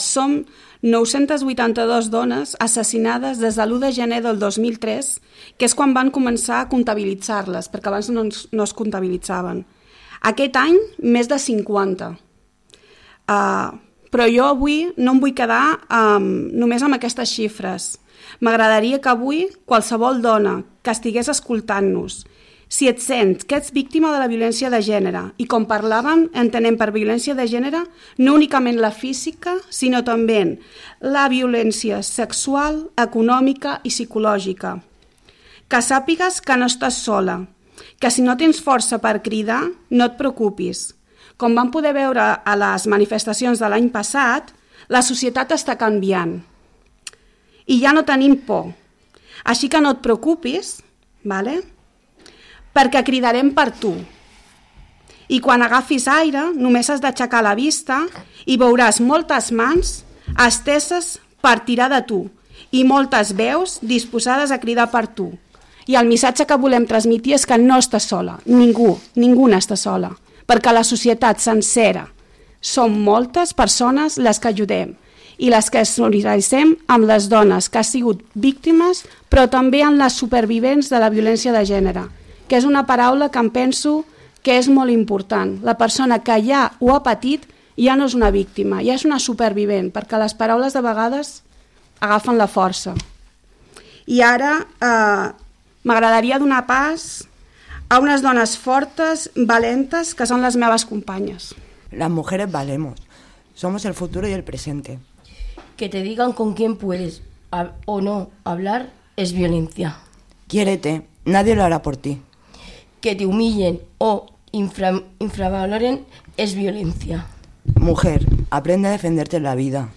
Avui han vingut als estudis de Ràdio Calella TV per fer públic el document en aquesta setmana plena d’actes que culminarà el diumenge amb la commemoració del Dia internacional per a l’eliminació de la violència envers les dones.